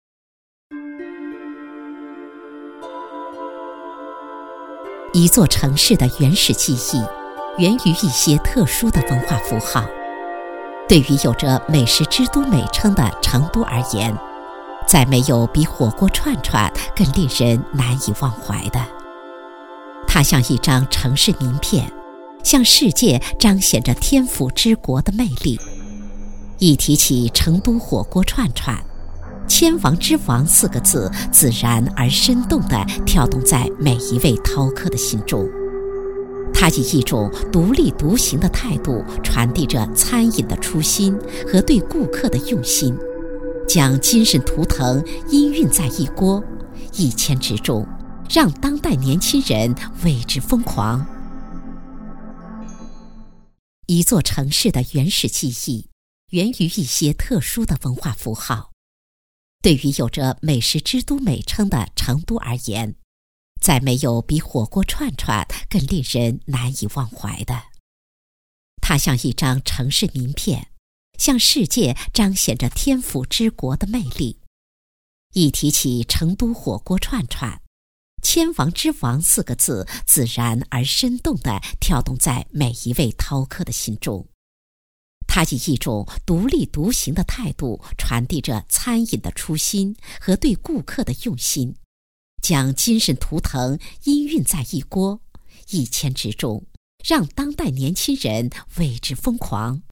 7 女国87_纪录片_舌尖_签王之王 女国87
女国87_纪录片_舌尖_签王之王.mp3